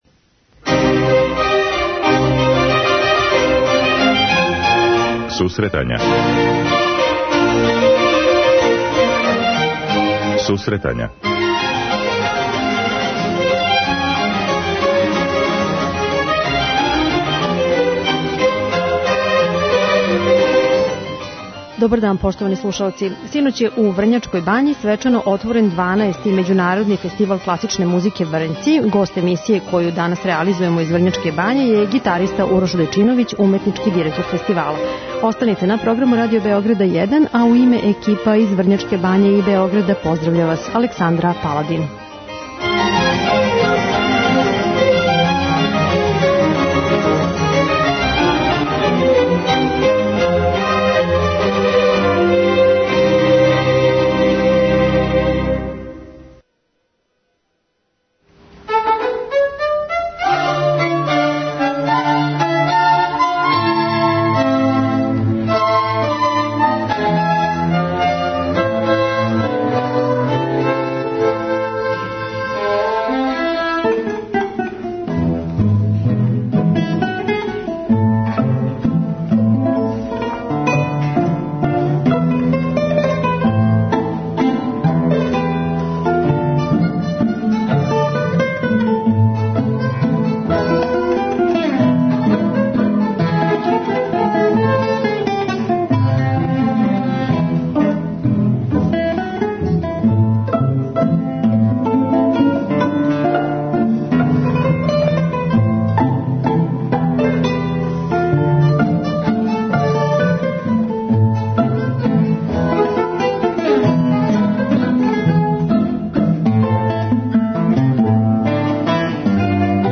У данашњој емисији, коју реализујемо из Врњачке бање, разговарамо са овим уметником, који ће представити и нова фестивалска издања која ће бити промовисана наредних дана.